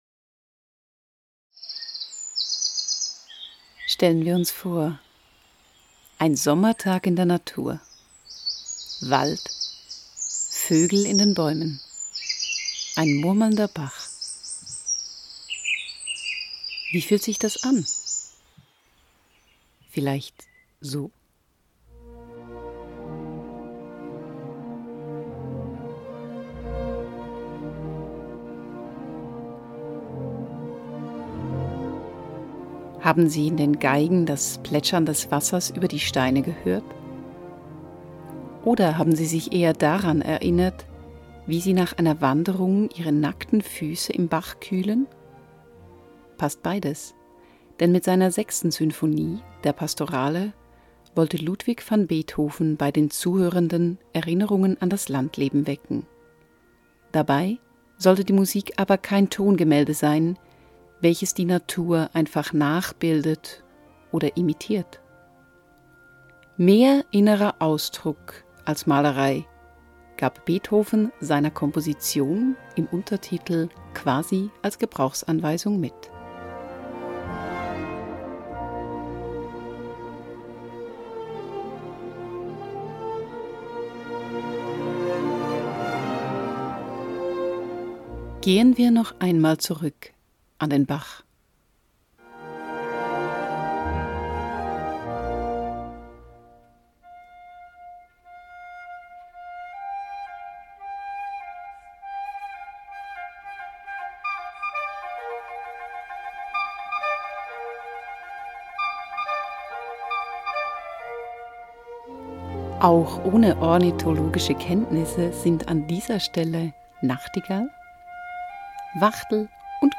beethoven_pastorale_einfuhrung_hslu.mp3